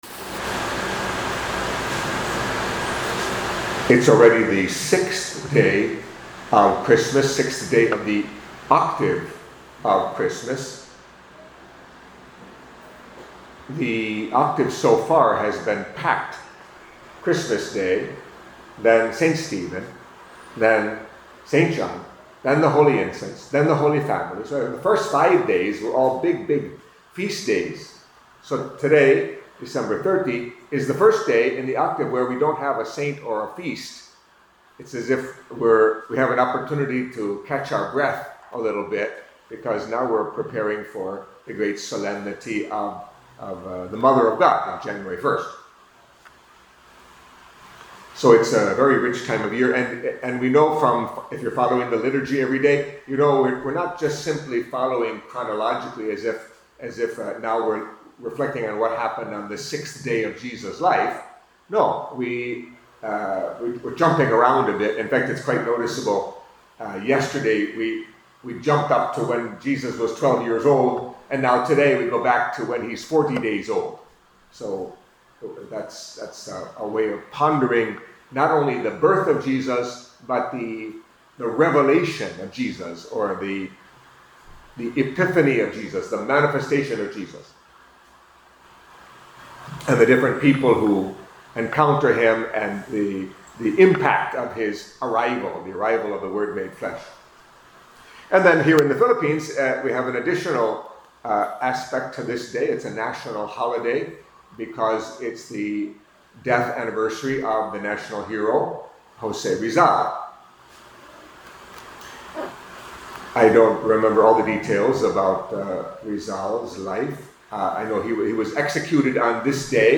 Catholic Mass homily for Monday, 6th Day in the Octave of Christmas